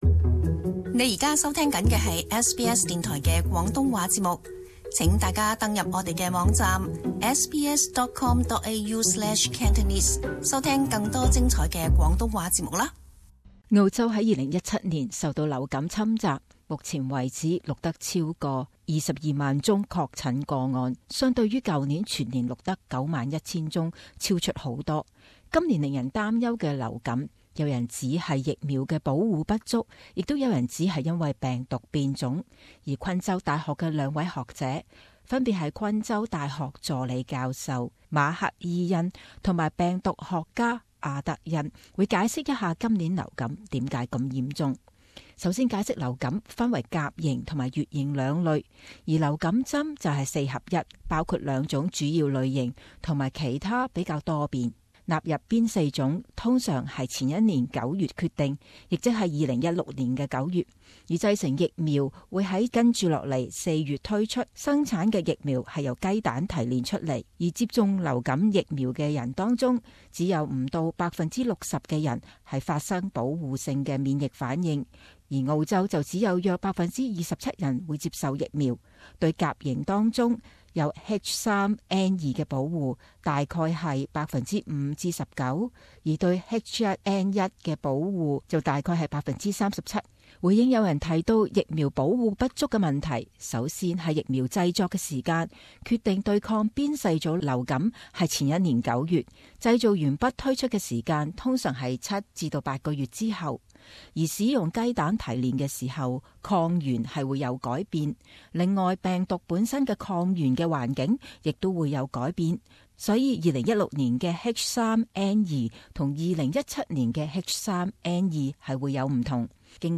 【時事報導】超級流感疫苗有用嗎？